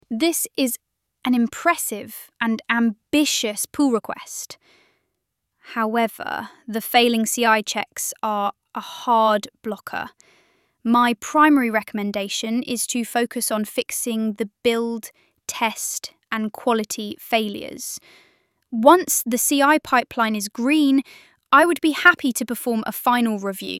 tts-demo.mp3